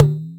Dholki Kit Packs